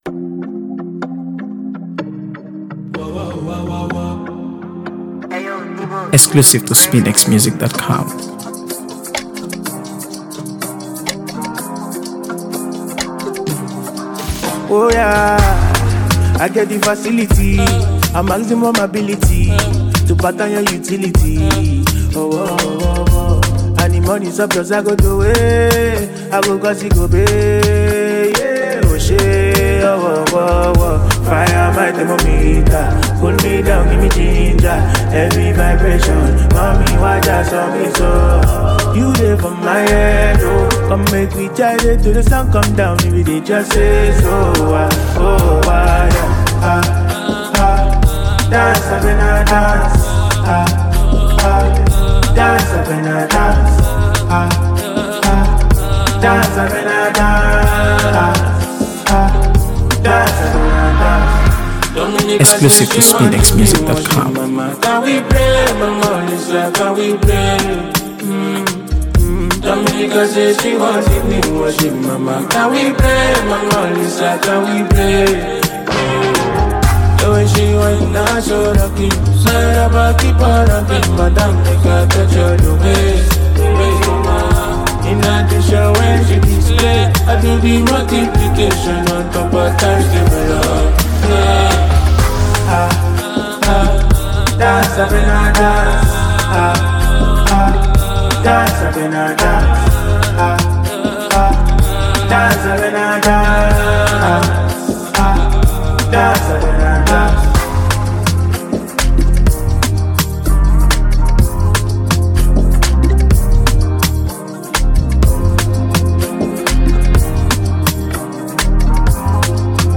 AfroBeats | AfroBeats songs
Afropop